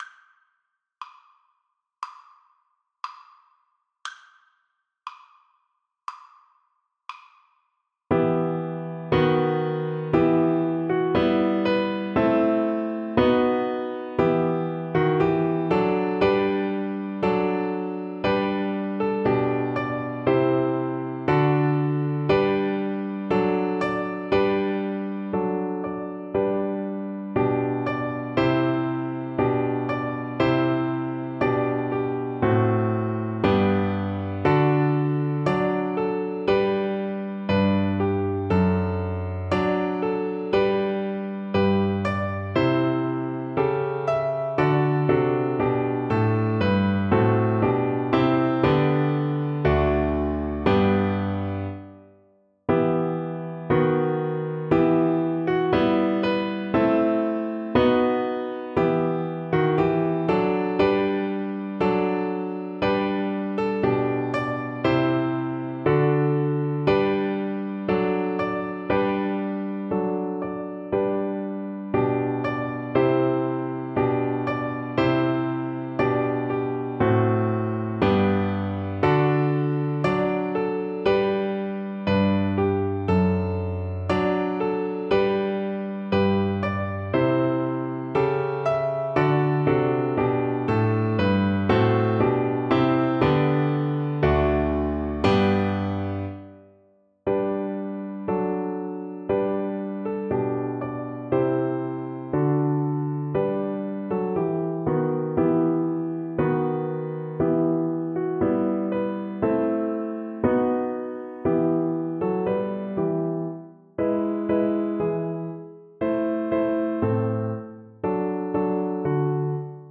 Play (or use space bar on your keyboard) Pause Music Playalong - Piano Accompaniment Playalong Band Accompaniment not yet available transpose reset tempo print settings full screen
Cello
E minor (Sounding Pitch) (View more E minor Music for Cello )
4/4 (View more 4/4 Music)
Classical (View more Classical Cello Music)